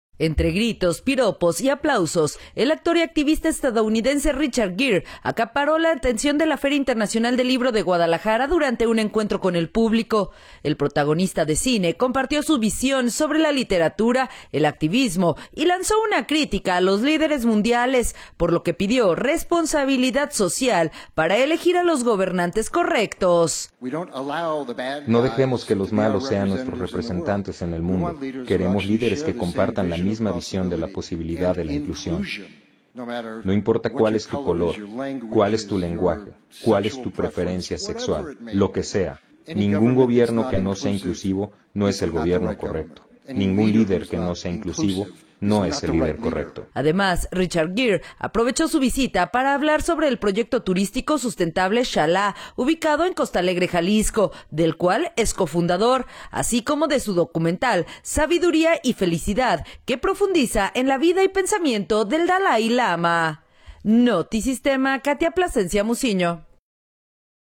Entre gritos, piropos y aplausos, el actor y activista estadounidense Richard Gere acaparó la atención de la Feria Internacional del Libro de Guadalajara durante un encuentro con el público.